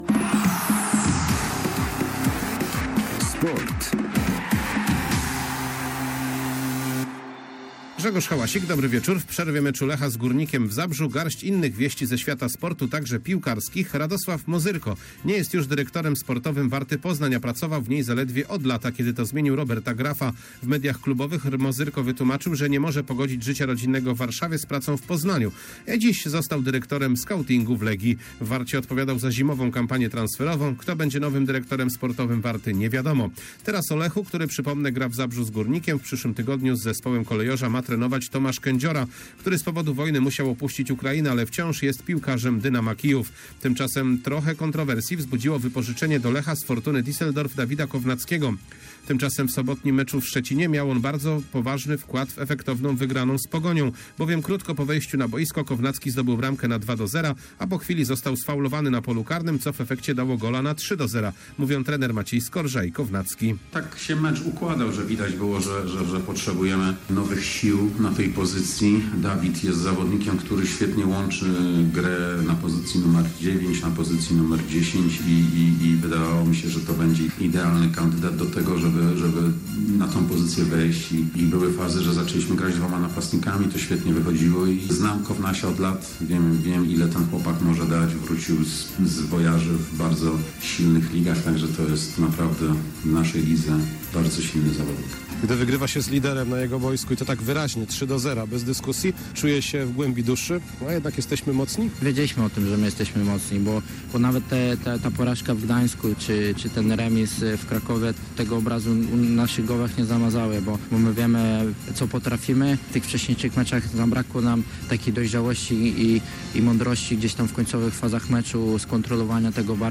Środowy serwis sportowy w przerwie meczu Lecha z Górnikiem, a zastanawiamy się w nim nad przydatnością w Lechu Dawida Kownackiego. Ponadto wieści z poznańskiej Warty i kilka słów o futsalistkach AZS UAM Poznań, które po zdobyciu akademickiego mistrzostwa Polski szykują się do walko o mistrzostwo futsalu.